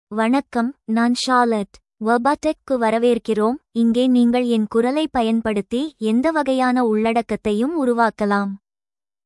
FemaleTamil (India)
Charlotte — Female Tamil AI voice
Voice sample
Female
Charlotte delivers clear pronunciation with authentic India Tamil intonation, making your content sound professionally produced.